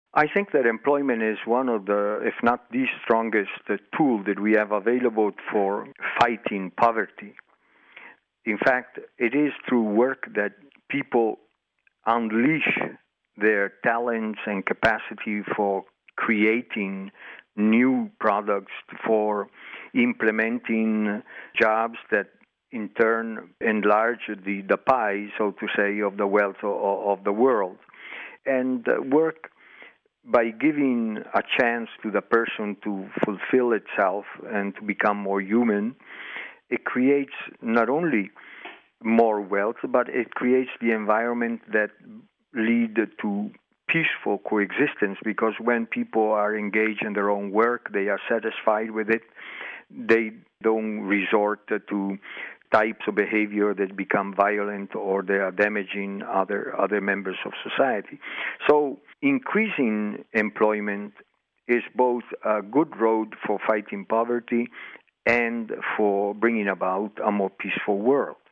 The Holy See’s permanent observer to the UN in Geneva, Archbishop Silvano Tomasi has been taking part in this ILO meeting. He told us that the struggle for decent work is also a key component of the struggle for peace among nations…